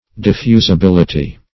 Search Result for " diffusibility" : The Collaborative International Dictionary of English v.0.48: Diffusibility \Dif*fu`si*bil"i*ty\, n. The quality of being diffusible; capability of being poured or spread out.